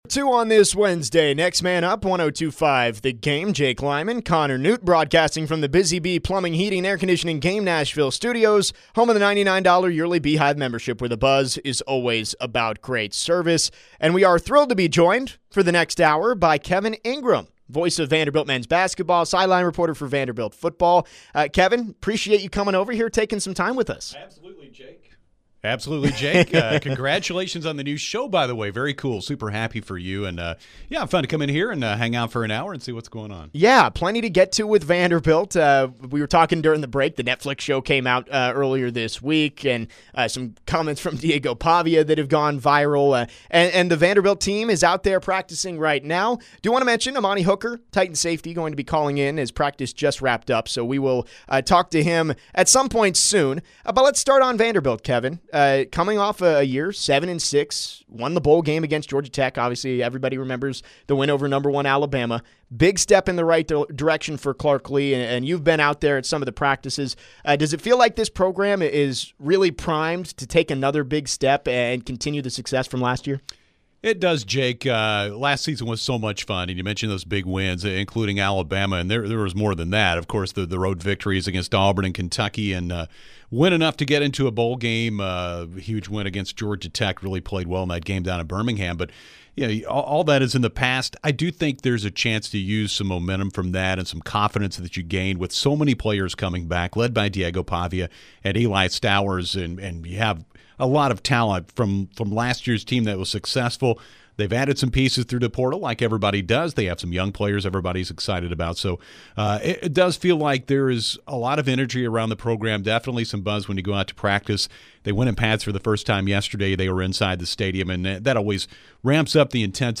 in studio to talk all things Vanderbilt Athletics